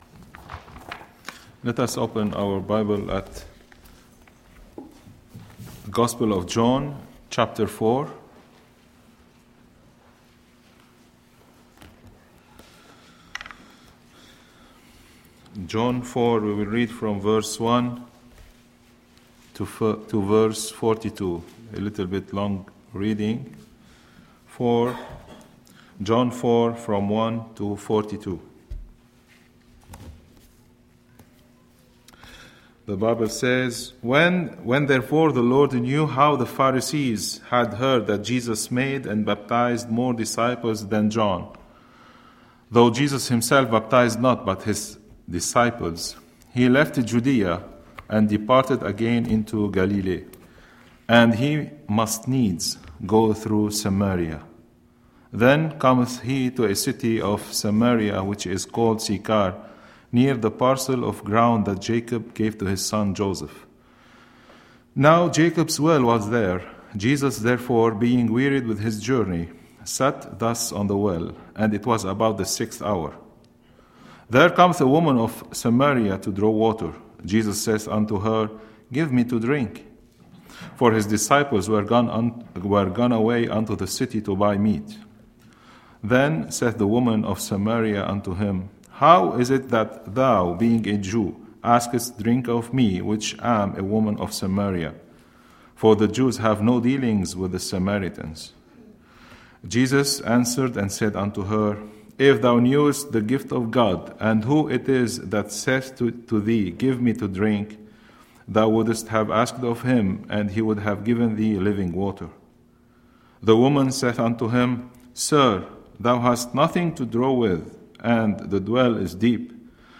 Monday, September 26, 2011 – Evening Message